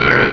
cries
-Replaced the Gen. 1 to 3 cries with BW2 rips.